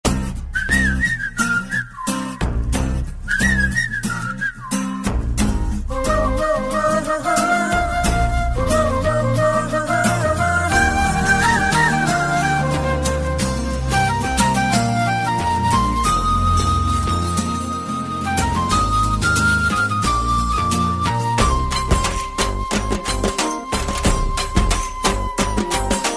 Techno rhythm Ringtone